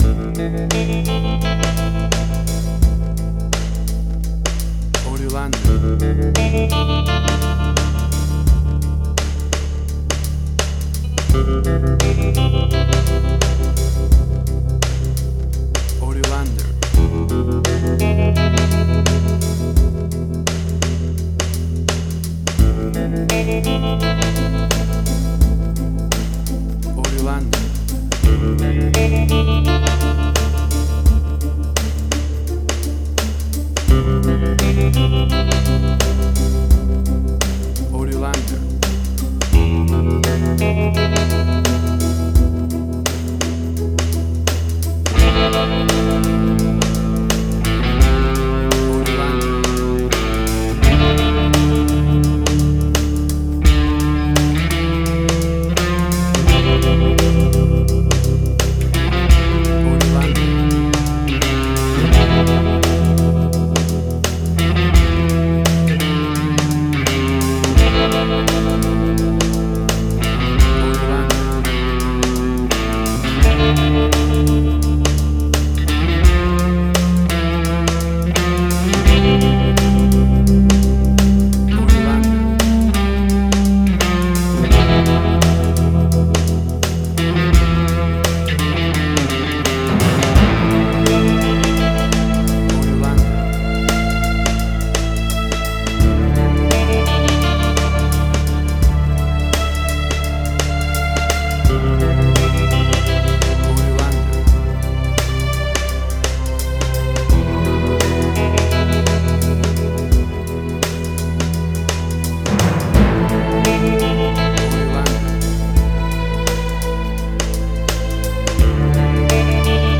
Hard Rock 102.jpg
Hard Rock, Similar Black Sabbath, AC-DC, Heavy Metal.
Tempo (BPM): 86